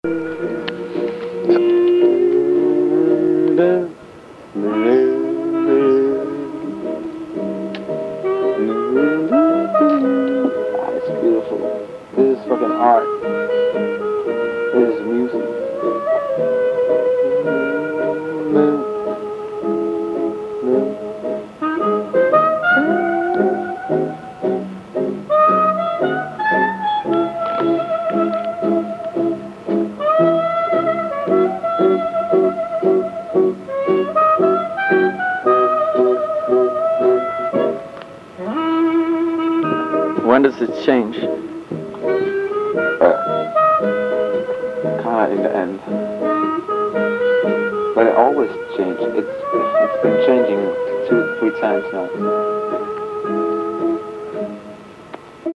hip-hop mini-epic